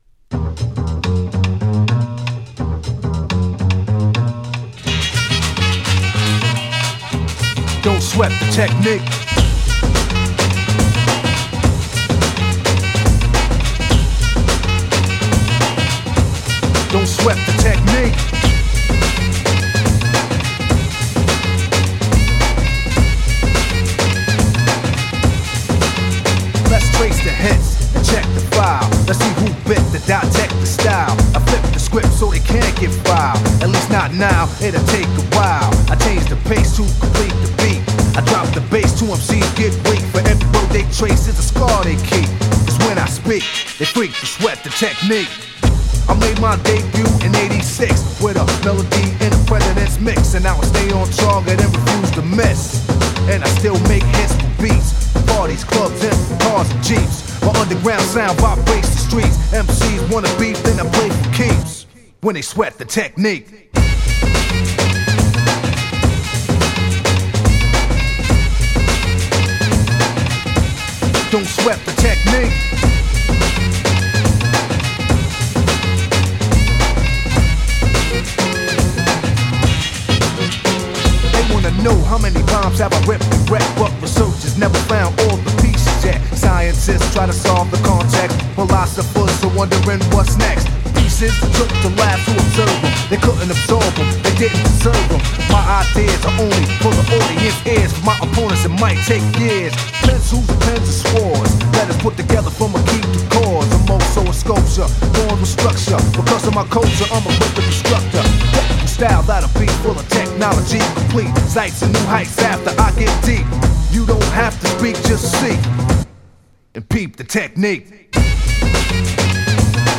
HIP HOP
HIP HOP CLASSIC !!